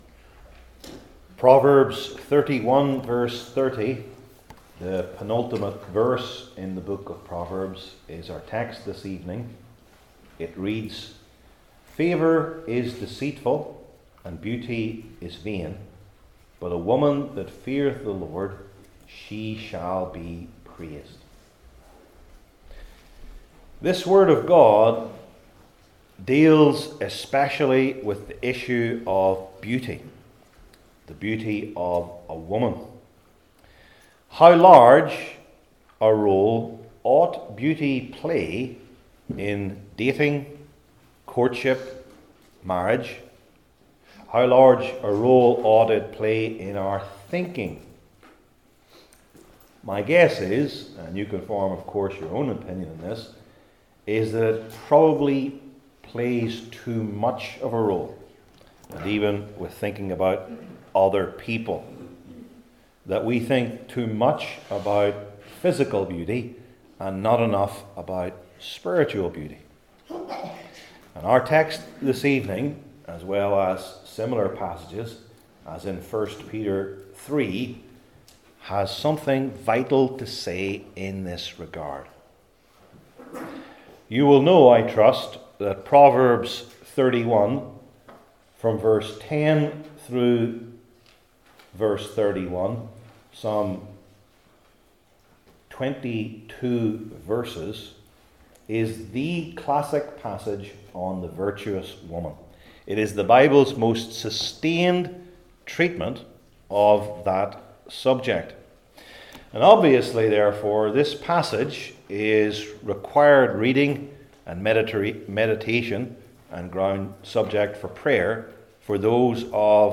Old Testament Individual Sermons I. The Vanity of Beauty II.